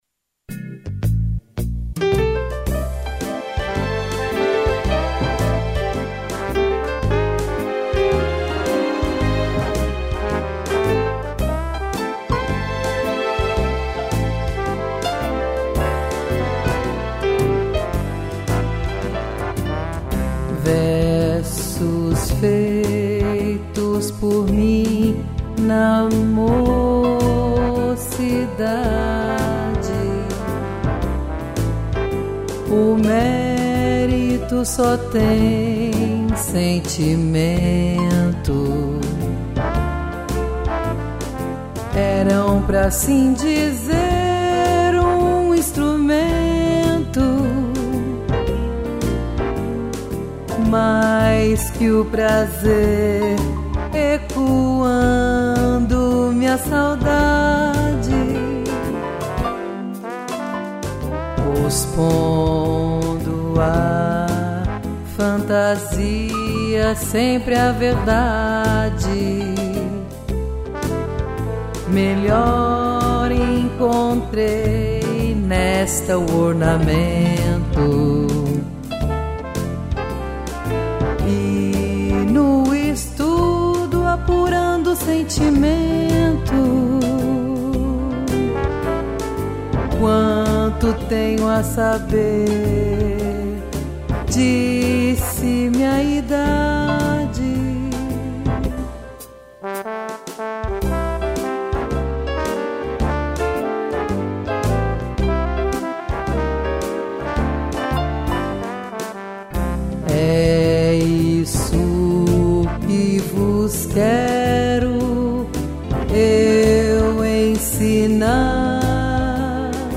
voz
piano e trombone